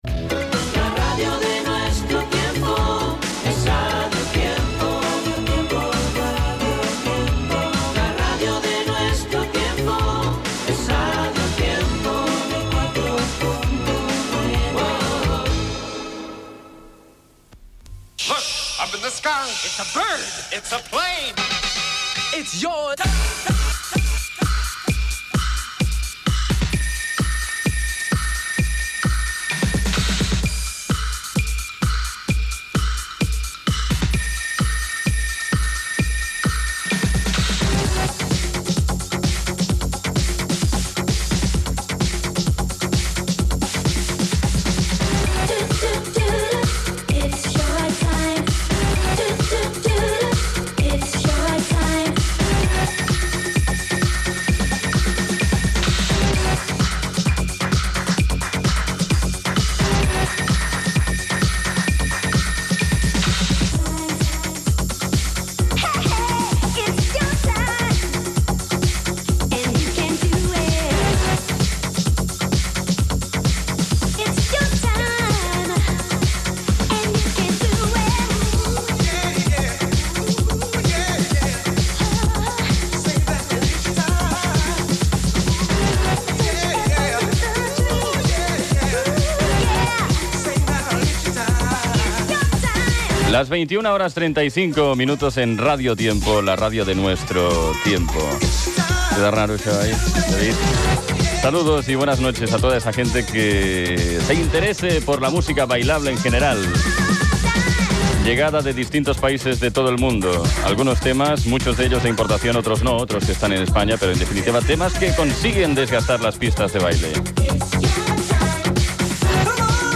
Indicatiu de la ràdio,sintonia, hora, identificació, presentació de la primera edició del programa amb els noms de l'equip, tema musical, comentari del tema que ha sonat, hora, indicatiu, publicitat, tema musical, indicatius del programa i de la ràdio, tema musical
Musical
FM